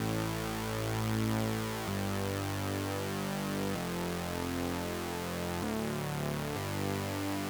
VDE 128BPM Notice Bass 2 Root A.wav